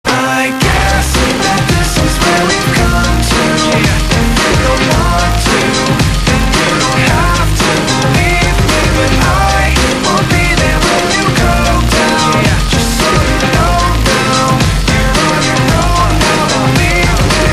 Народ подскажите плиз как ета песня называется вчера с радио записал!